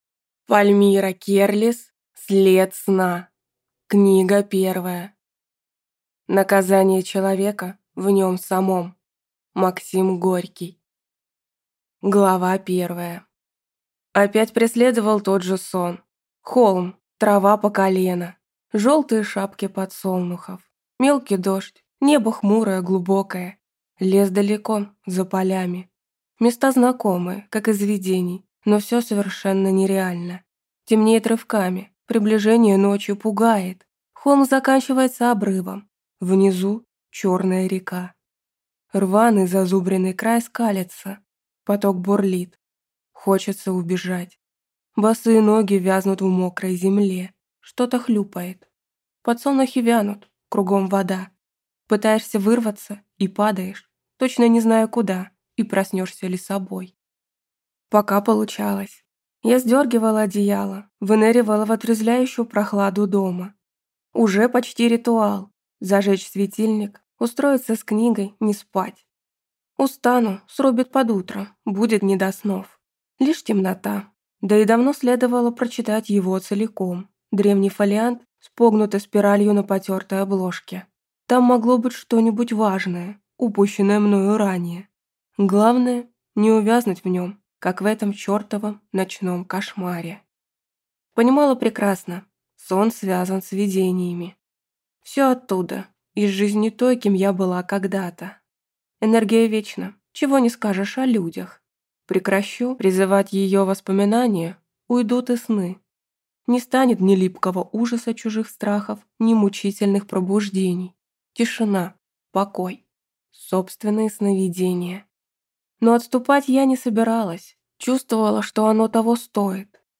Аудиокнига След сна. Книга 1 | Библиотека аудиокниг